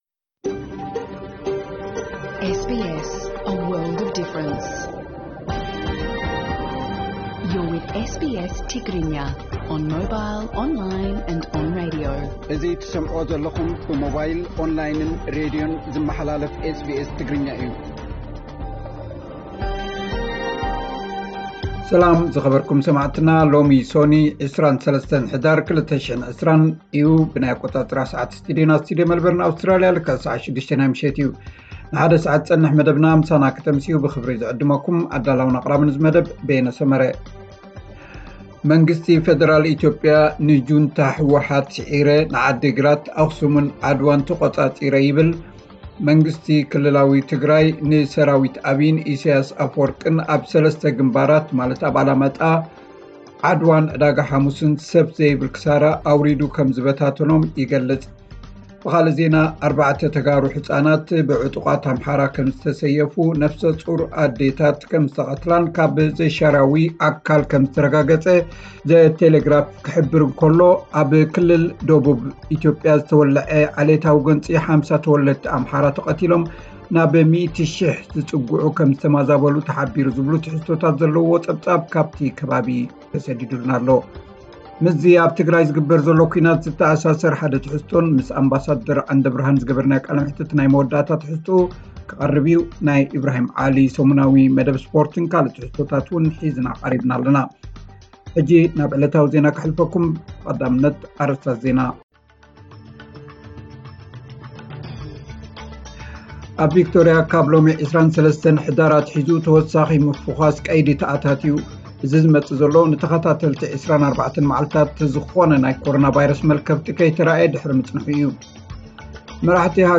ዕለታዊ ዜና 23 ሕዳር 2020 SBS ትግርኛ